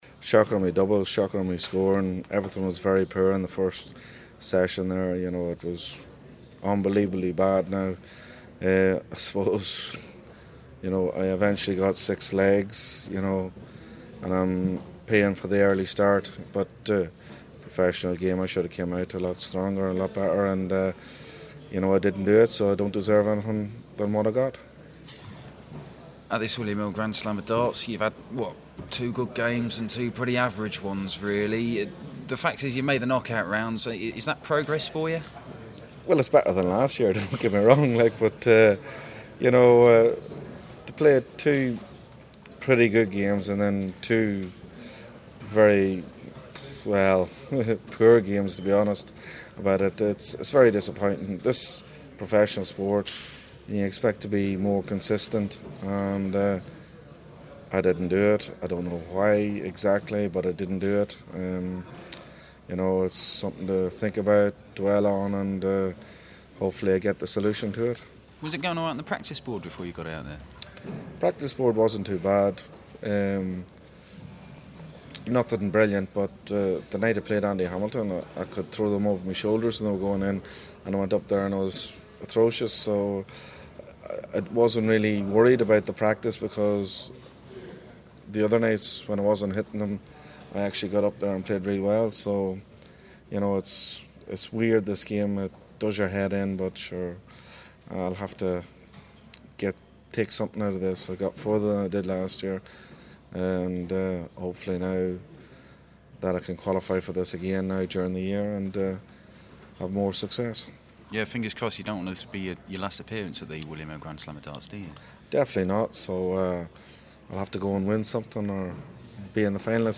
William Hill GSOD - Dolan Interview (Last 16)